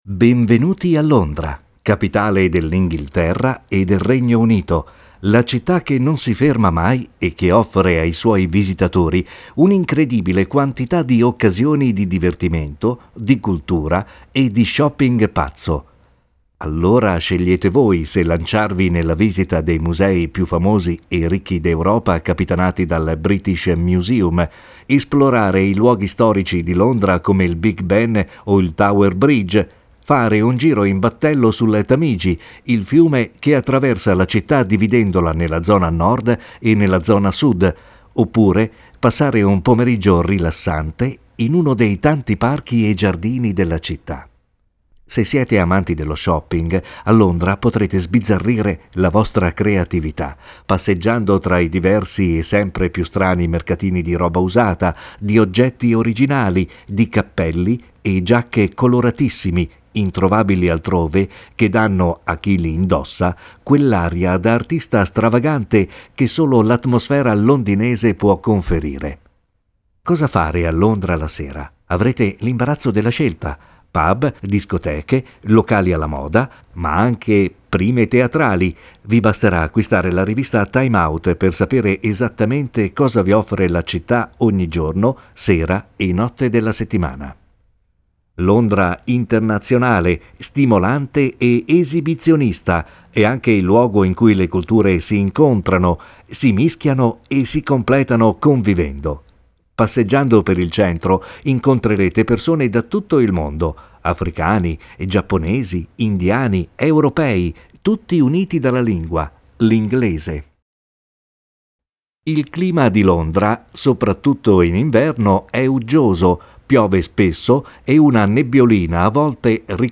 Offers professional voice-over service for advertising, audio tour guides, e-learning, documentaries, jingles, voice mail, promo, audiobook, voice over, corporate video, animatic, industrial videos, video tutorials , radio programs and many more.